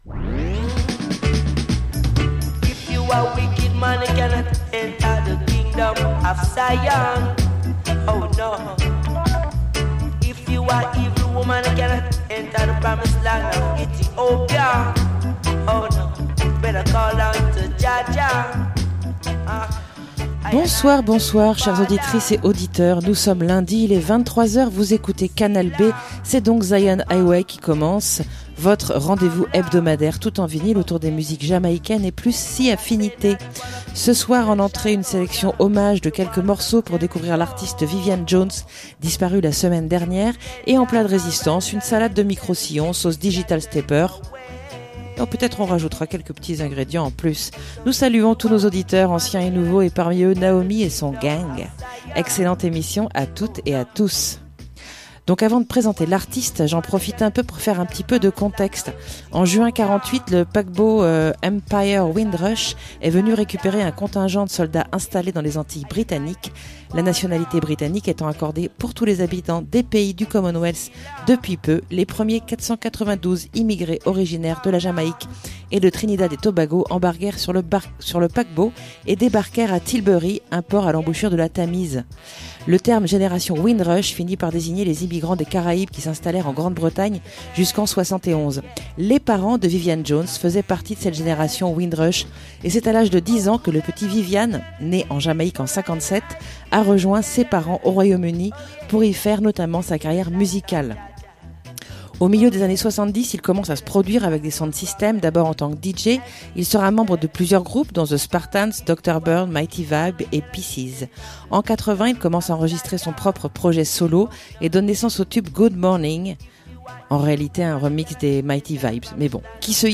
roots, digital et stepper